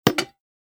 دانلود آهنگ موس 23 از افکت صوتی اشیاء
جلوه های صوتی
برچسب: دانلود آهنگ های افکت صوتی اشیاء دانلود آلبوم صدای کلیک موس از افکت صوتی اشیاء